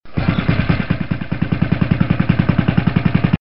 Звук запуска мотора лодки и работа двигателя